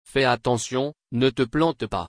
crashfr.mp3